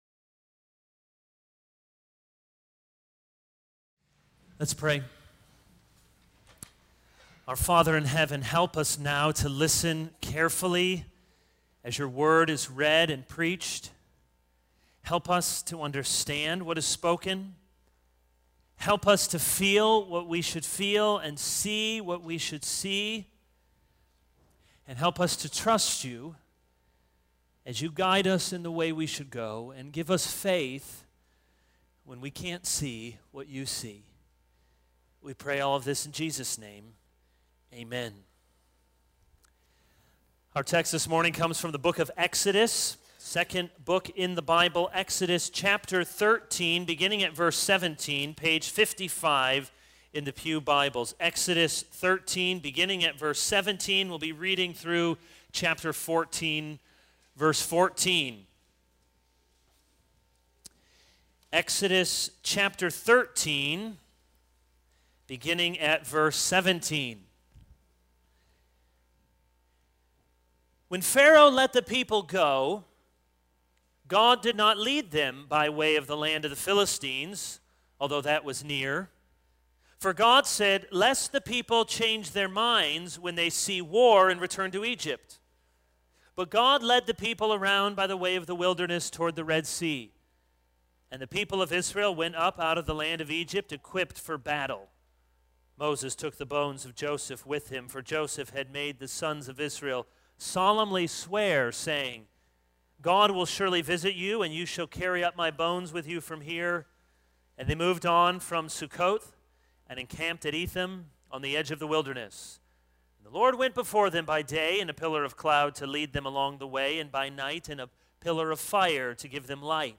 This is a sermon on Exodus 13:17-14:14.